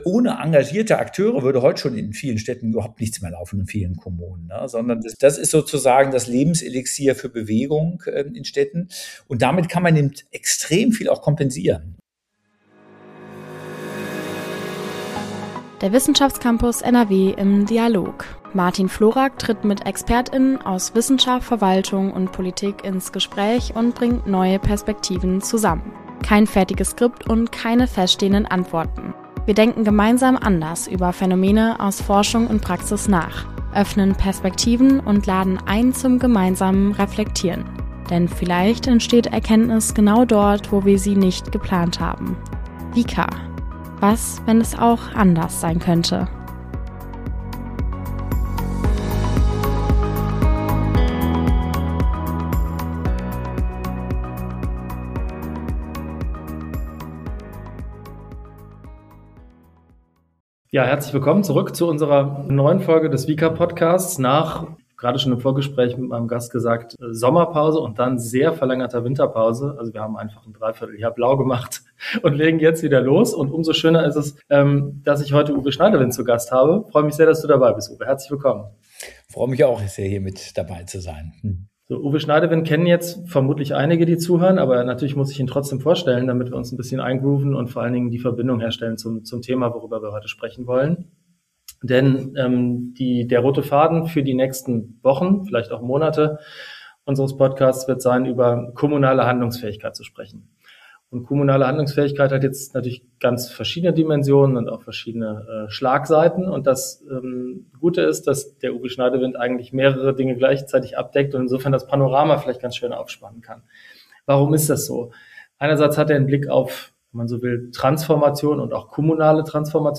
Im Gespräch geht es um die Handlungsfähigkeit von Kommunen, um die Rolle engagierter Akteurinnen und Akteure in Verwaltung und Stadtgesellschaft sowie um die Frage, ob Reformdiskussionen über Staat und Verwaltung zu stark auf die Bundesebene fokussiert sind und warum der kommunalen Ebene als zentralem Ort der Umsetzung dabei oft zu wenig Aufmerksamkeit zukommt.